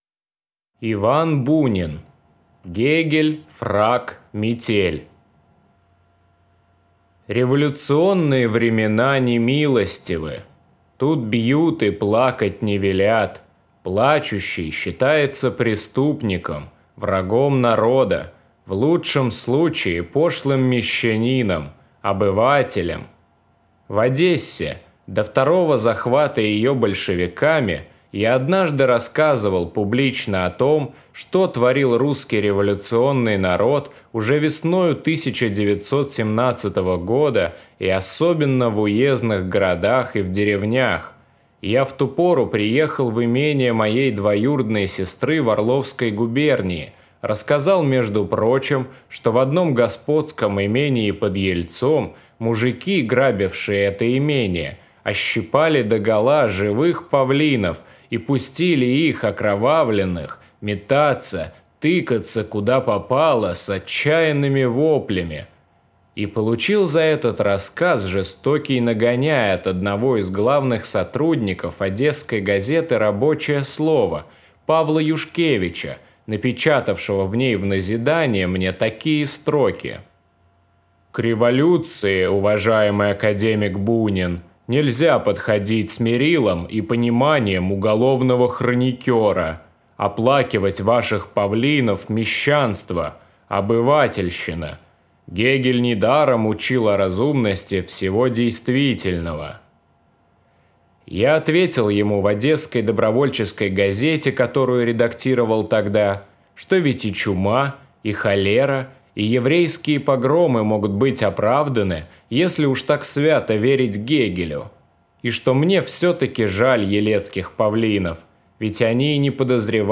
Аудиокнига Гегель, фрак, метель | Библиотека аудиокниг